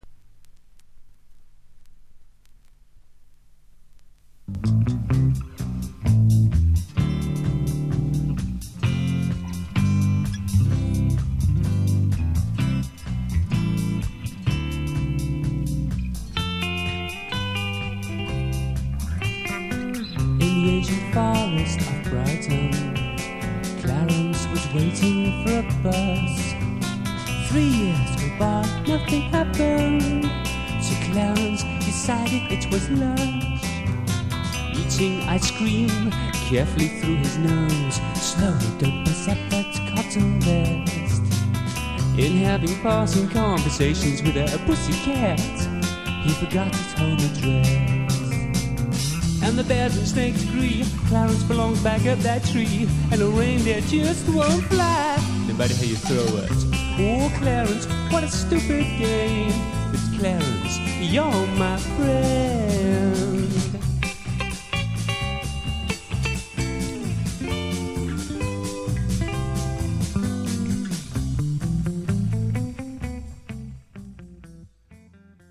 洒脱なお惚けスイング・ネオアコ